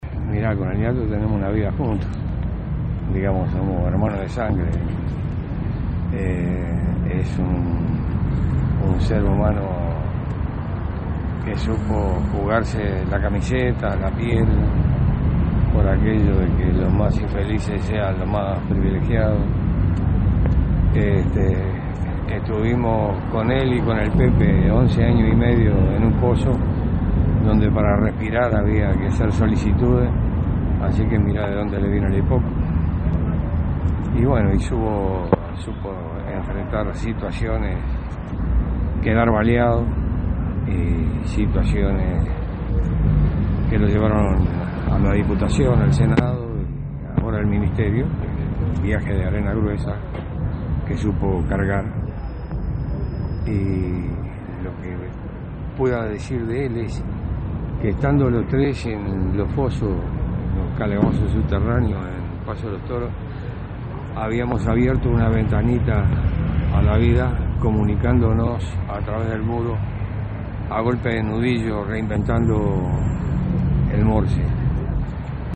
Varias personalidades políticas se hicieron presentes en el velatorio. Uno de ellos fue Mauricio Rosencof, que dedicó unas palabras a su difunto amigo: